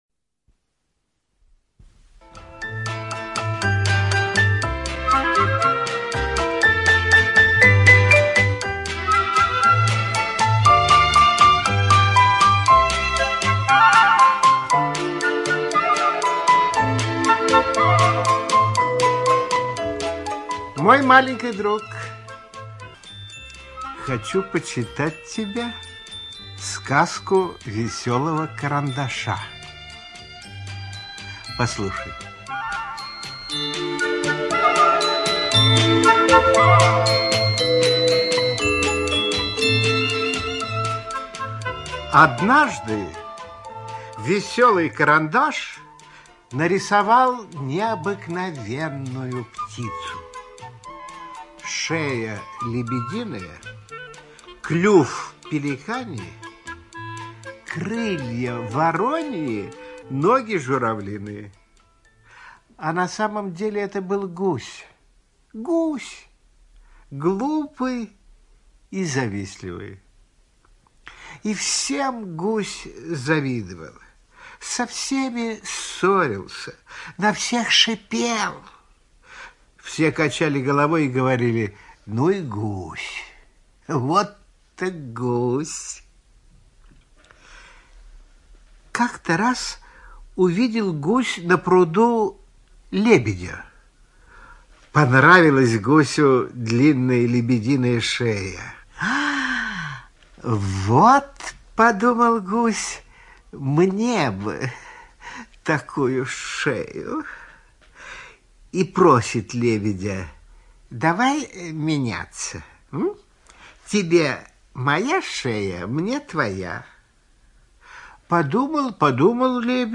Аудиосказка «Это что за птица»